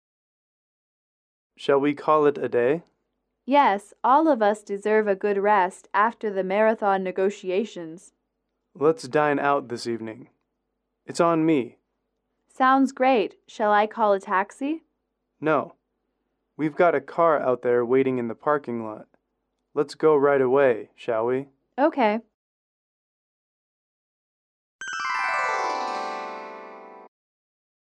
英语主题情景短对话53-3：谈判后共进晚餐（MP3)